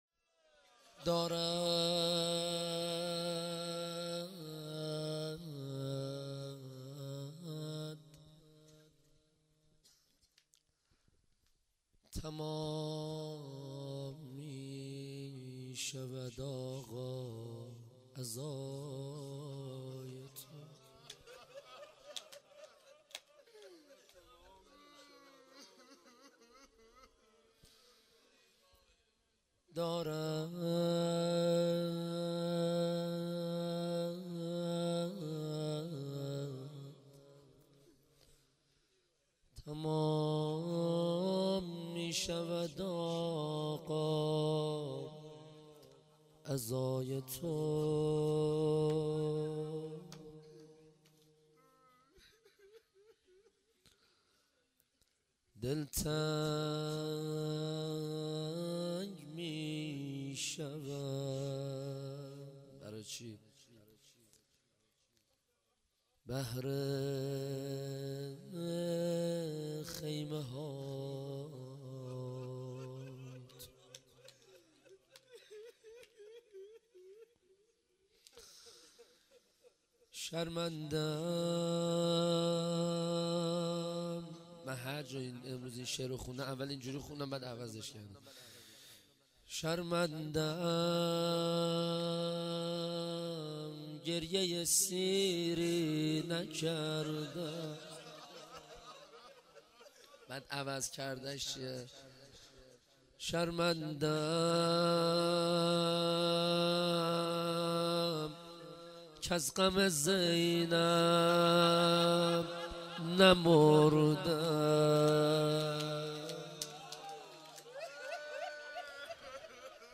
روضه شهادت امام رضا